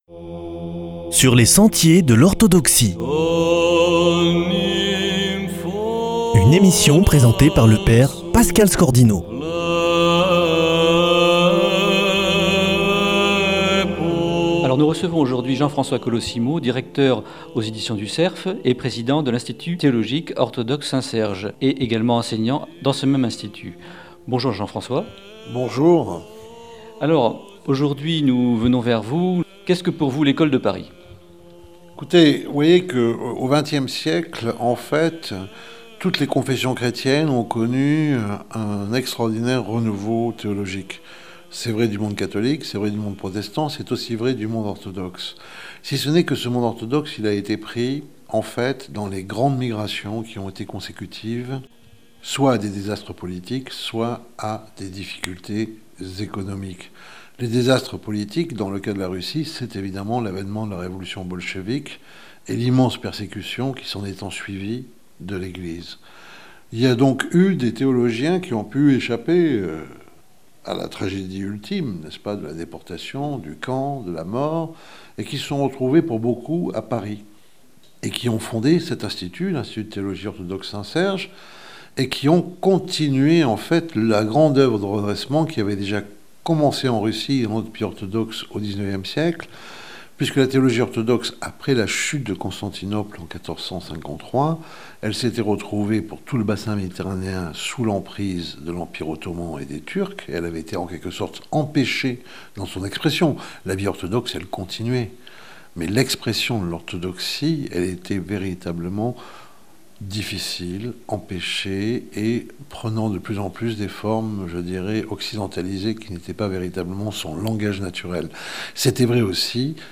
Jean-François Colosimo, historien des religions, directeur des Éditions du Cerf et maître de conférence à l'Institut de théologie orthodoxe (…)